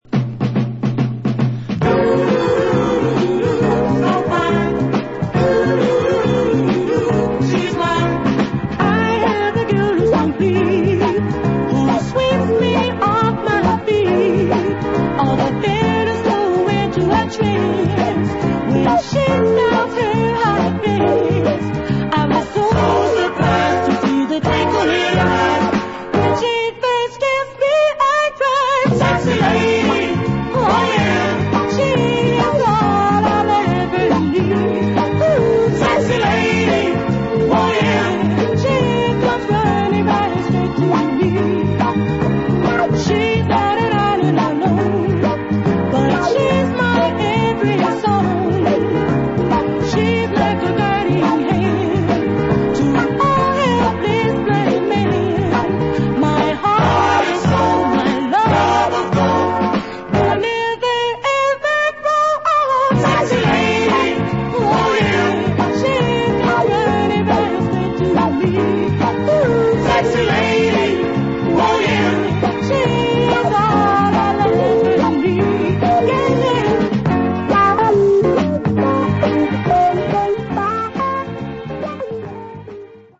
Early 70's brilliant finger snapping Northern Soul dancer.
Good time 70's Northern Soul at it's best.